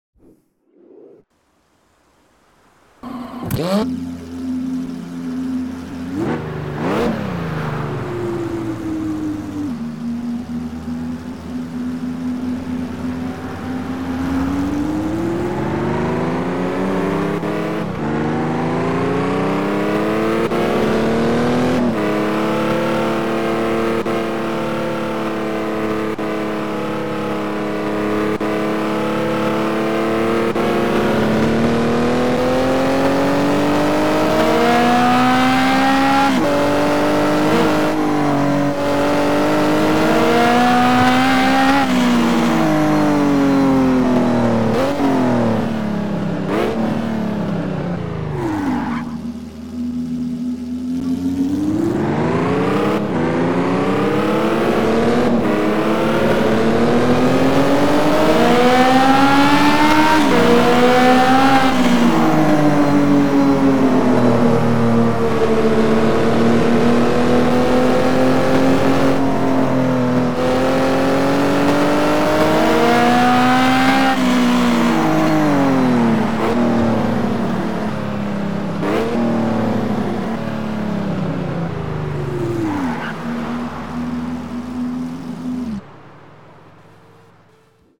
- Ferrari F430 Spider